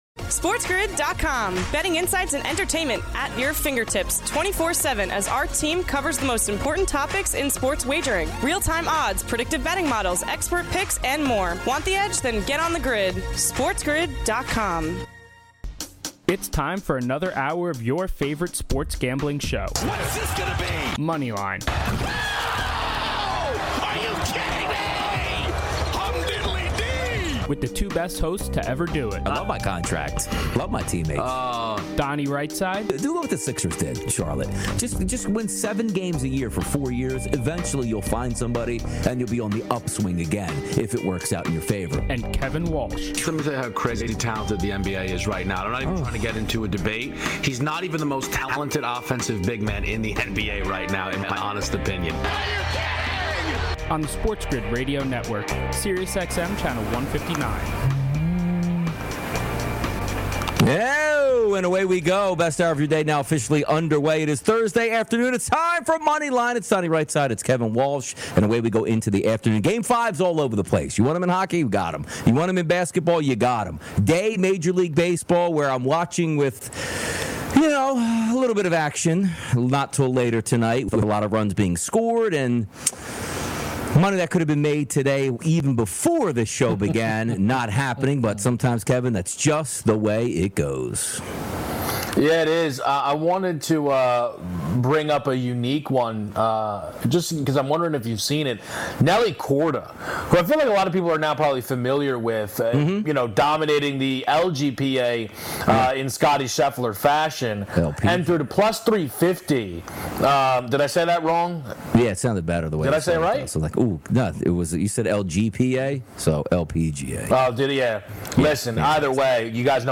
Then the guys look at the Game 5 scenarios in the NHL playoffs and the NBA Western Conference Finals. Plus, a caller has a new nickname for the Moneyline Boys.